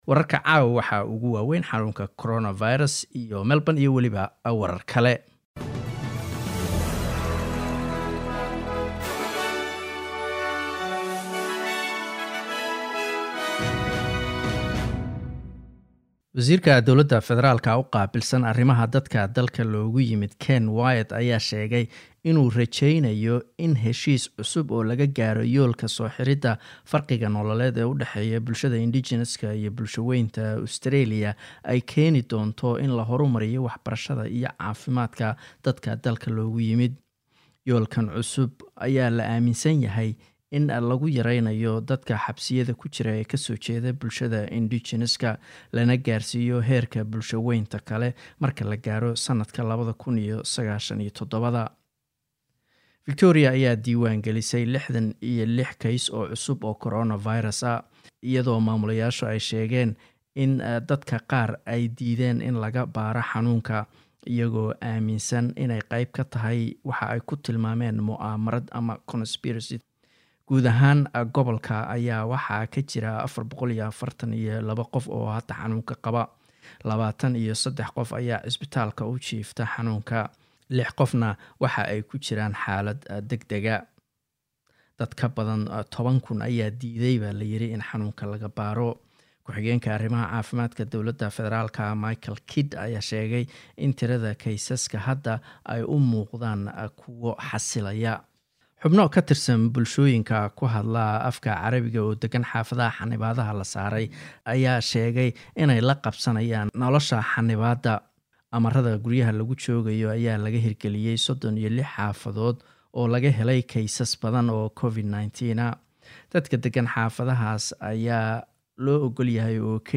wararka_sbs_somali_news_03-07.mp3